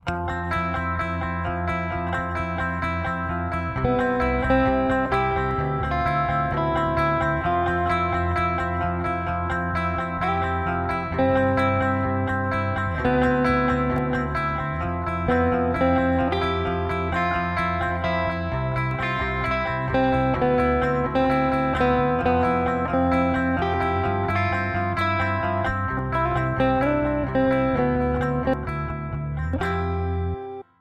Guitars were recorded with Pod Studio UX1.